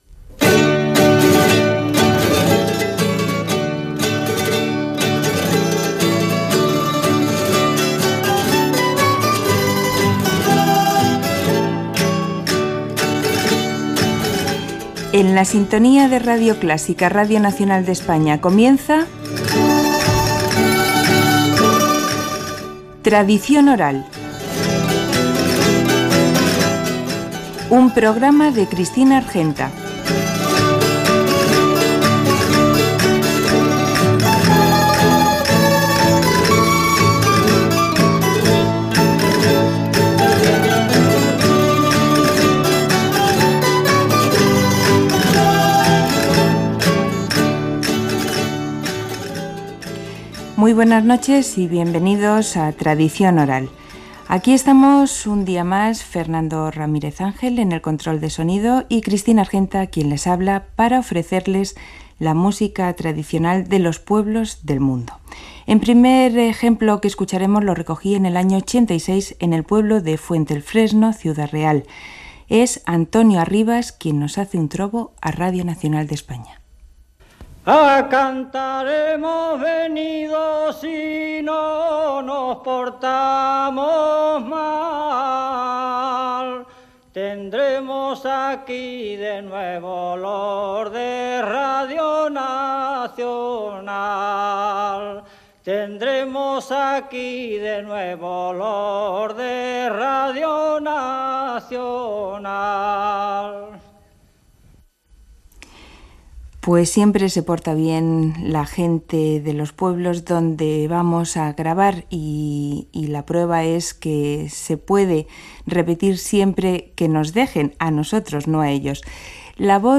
Sintonia, presentació, "trobo" dedeicat a Radio Nacional, ronda en víspera de Sant Isidre
Musical